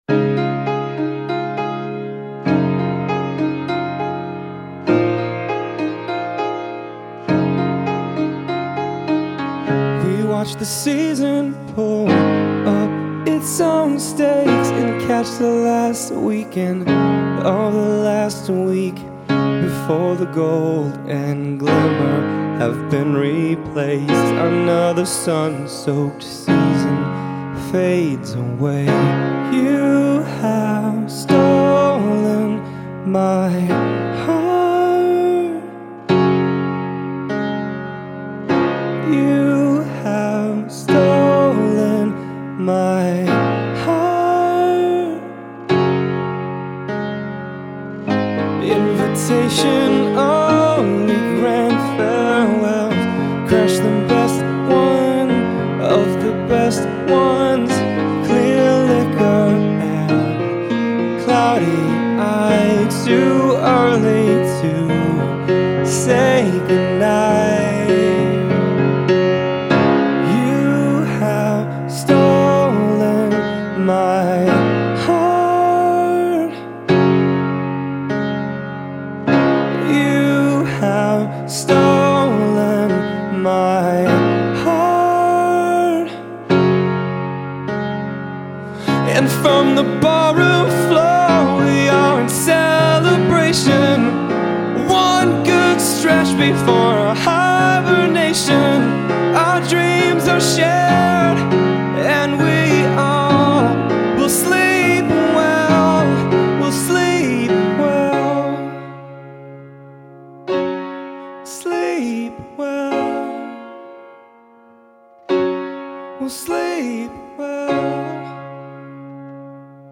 Genre: Pop-Rock, Soft-Rock, Acoustic.